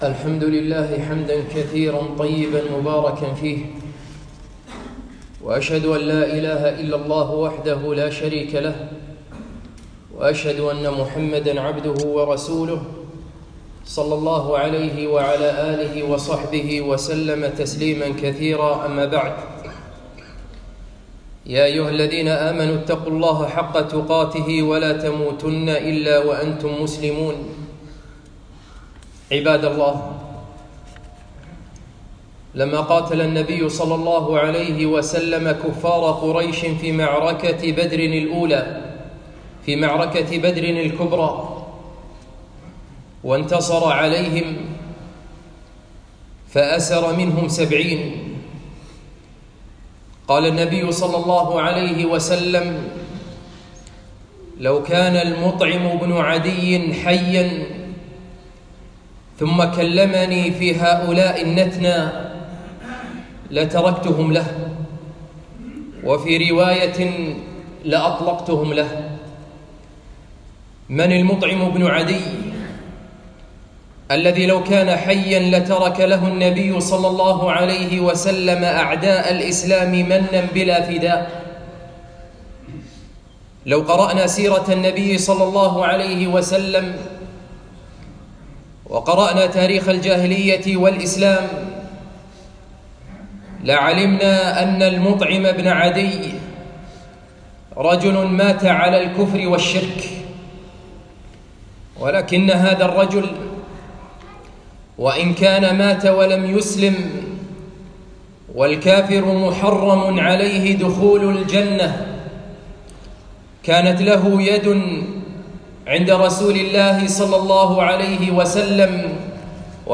خطبة - احفظوا للكرام معروفهم